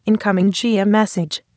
GM_alert.wav